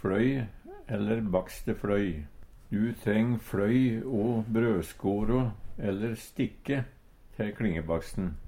fløy / bakstefløy - Numedalsmål (en-US)